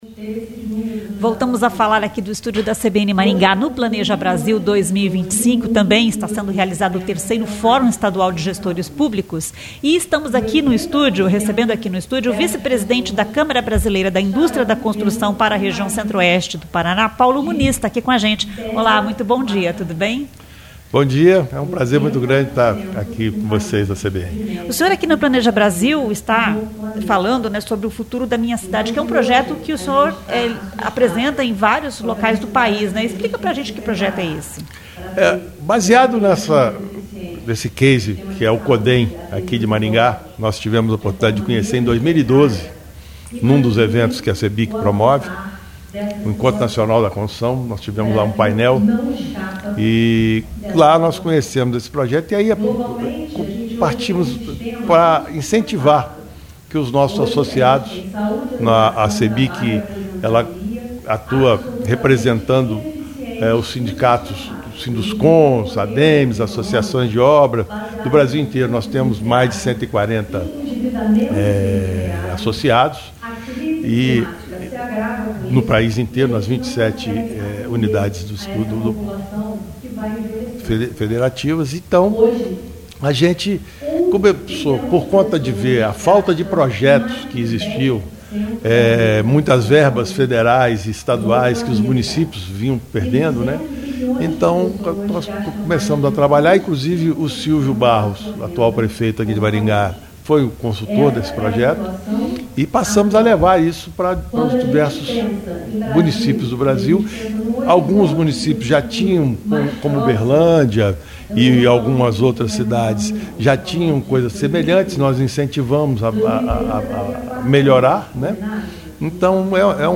A entrevista foi realizada no estúdio móvel CBN instalado no local do evento.